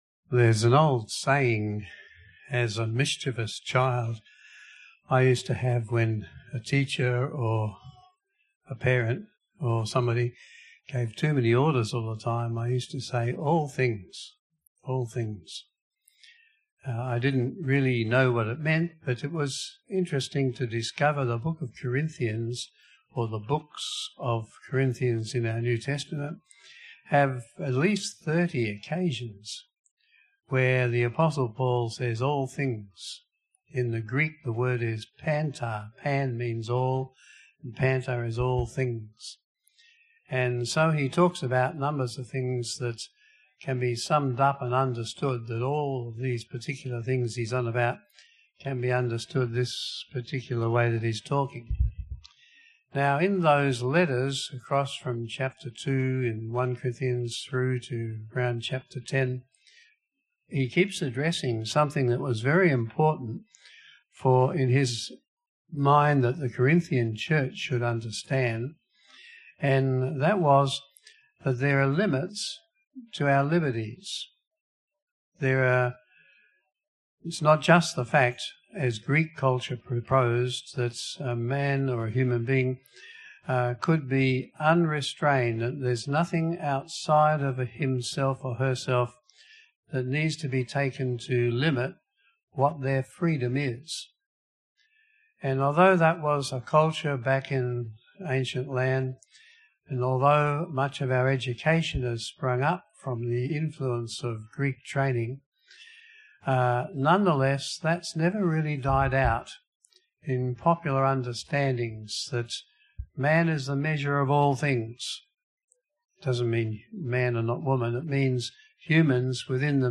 Passage: 1 Corinthians 2:10-16, 3:4-23, 6:12-18 Service Type: PM Service